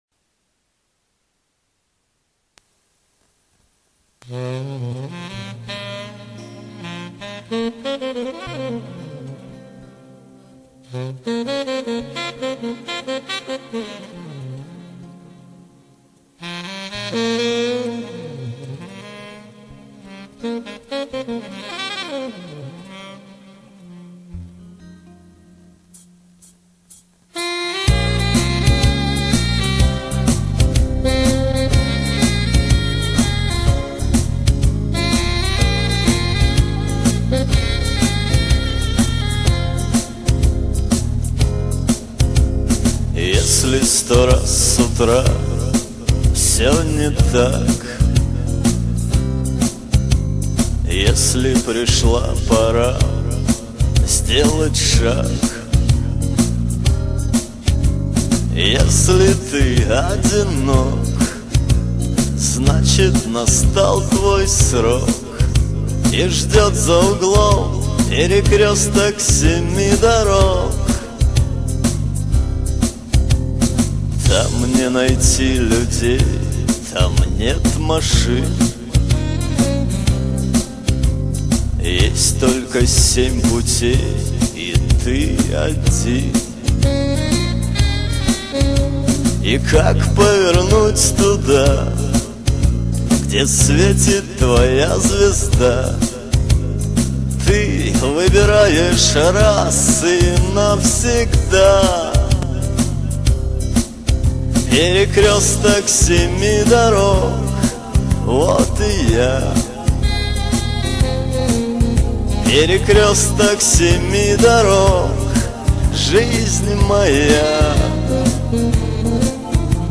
Хотя голос у вас приятный!